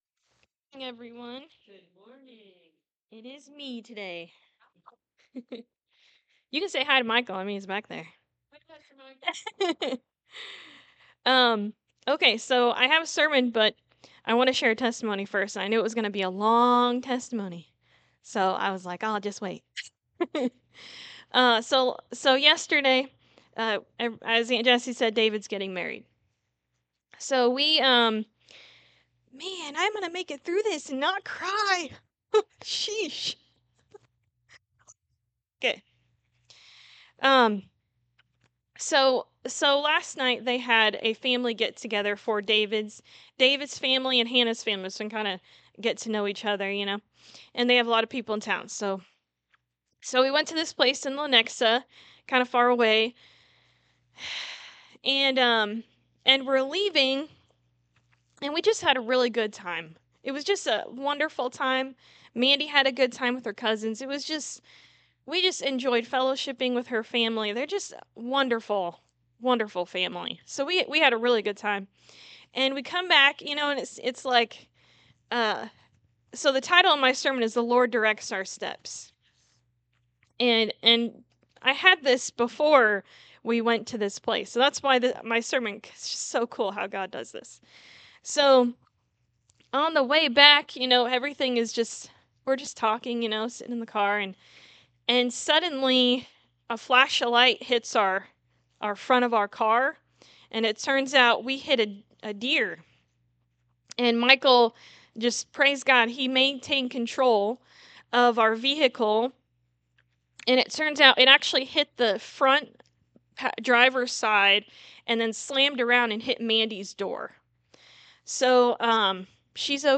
Jeremiah 29:11 Service Type: Sunday Morning Service From crossing the Red Sea to crossing the River Jordan
Sunday-Sermon-for-November-2-2025.mp3